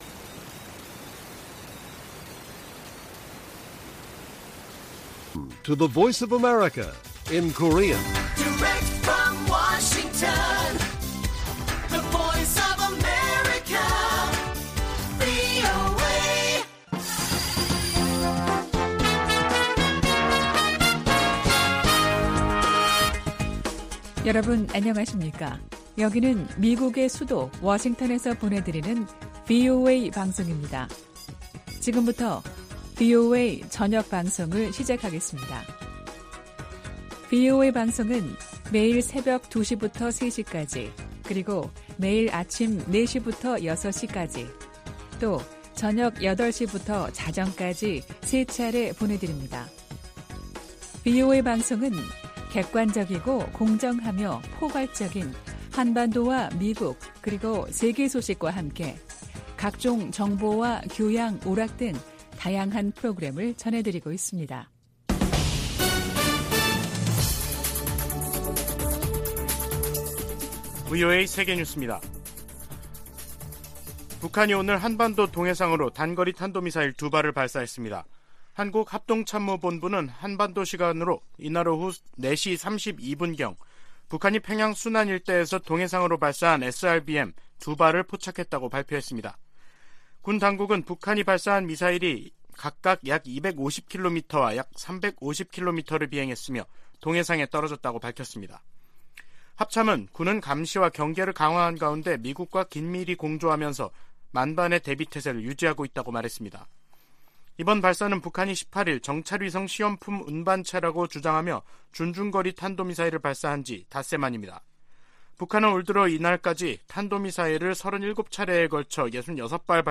VOA 한국어 간판 뉴스 프로그램 '뉴스 투데이', 2022년 12월 23일 1부 방송입니다. 북한이 정찰위성 시험이라며 탄도미사일을 발사한 지 닷새만에 또 다시 탄도미사일을 발사했습니다. 미국 백악관은 러시아의 우크라이나 침공을 지원하는 현지 용병업체에 북한이 로켓과 미사일을 전달했다며, 북한-러시아 간 무기 거래 사실을 확인했습니다.